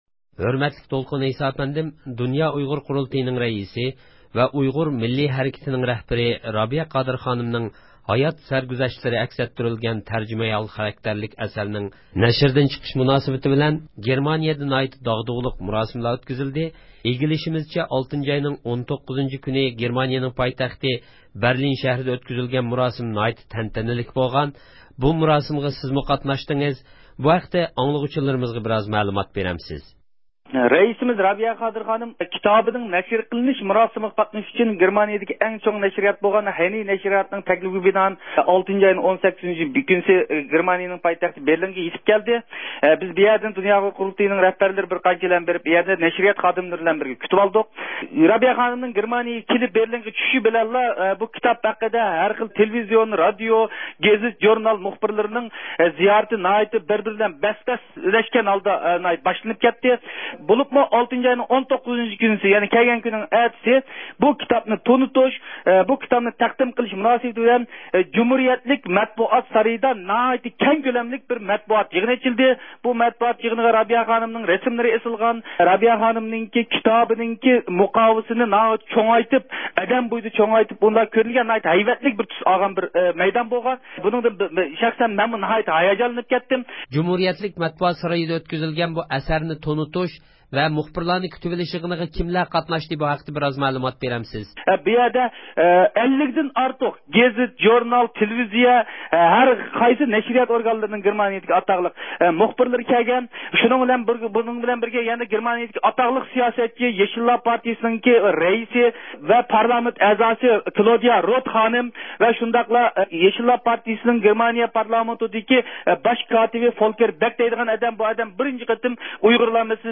رابىيە قادىر خانىمنىڭ تەرجىمىھال خاراكتېرلىك ئەسىرىنىڭ نەشىردىن چىقىش مۇناسىۋىتى بىلەن، دولقۇن ئەيسا سۆھبەتتە – ئۇيغۇر مىللى ھەركىتى